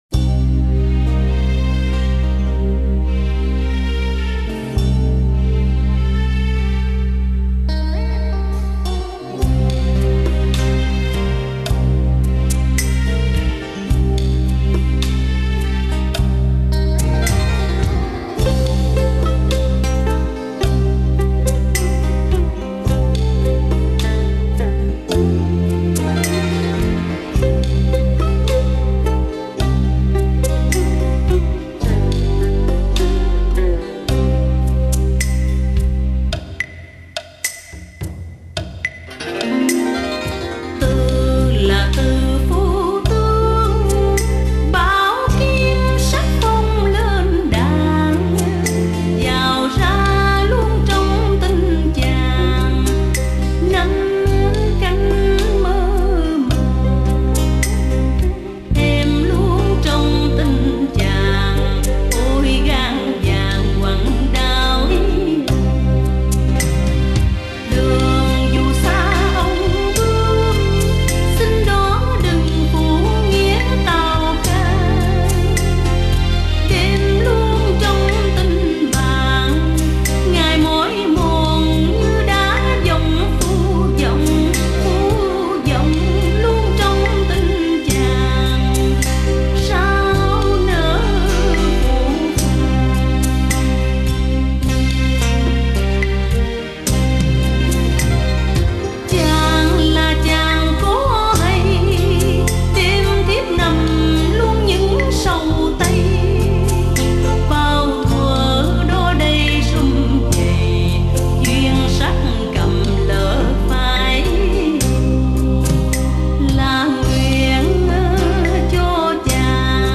Xin mời nghe bài Dạ Cổ Hoài Lang tác giả Sáu Lầu, ca sĩ Hương Lan